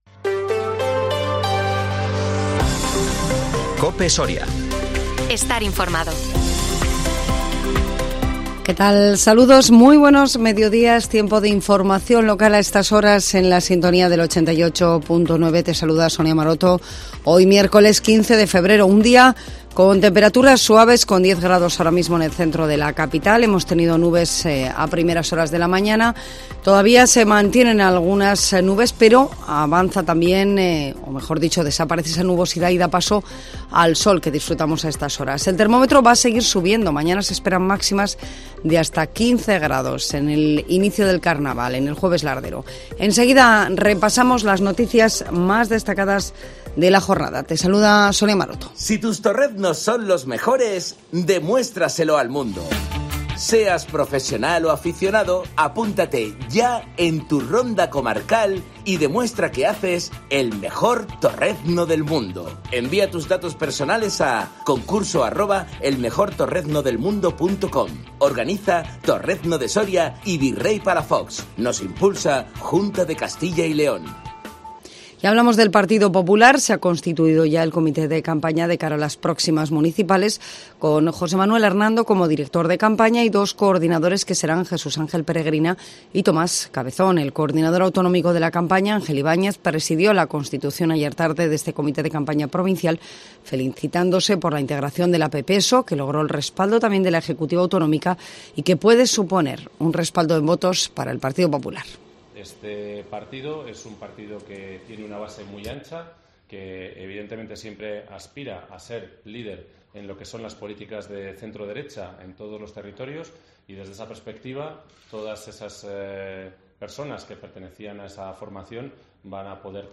INFORMATIVO MEDIODÍA COPE SORIA 15 FEBRERO 2023